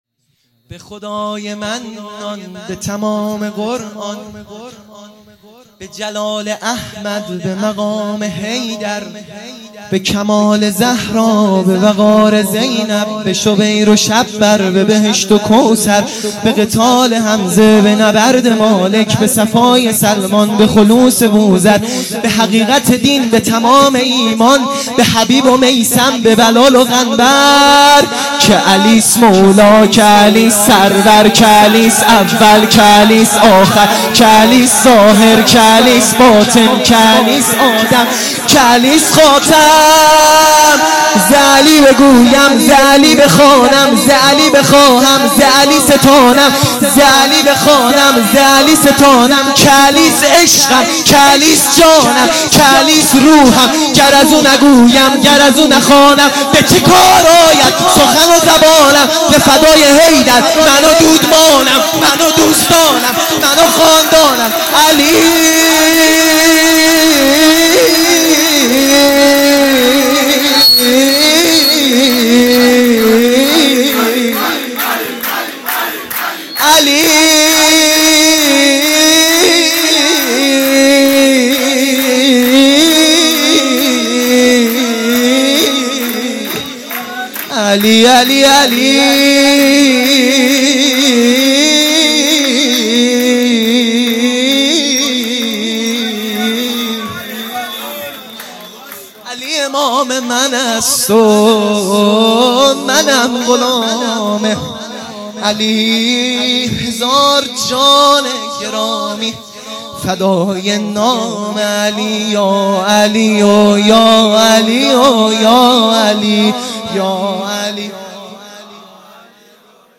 بحرطویل ا به خدای منان